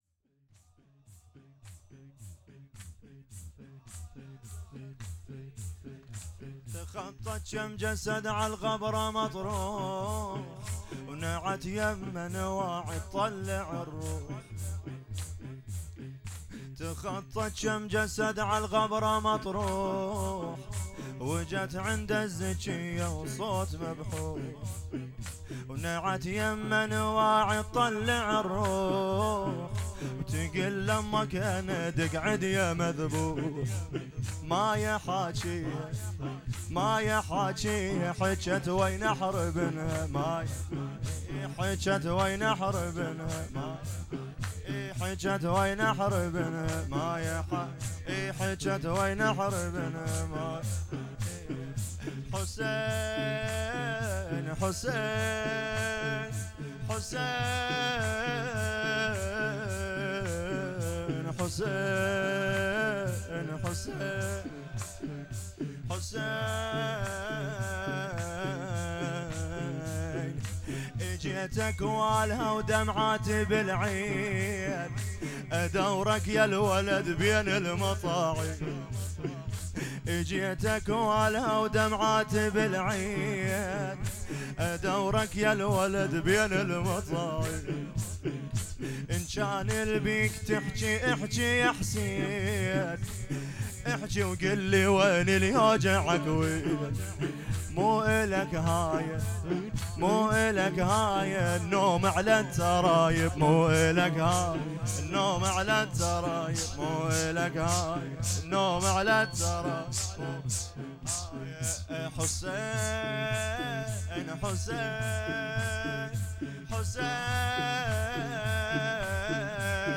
زمینه عربی شب اول فاطمیه دوم
مراسم شهادت حضرت زهرا س شب اول